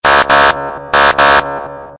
Air Horn Siren
Air-Horn-Siren.mp3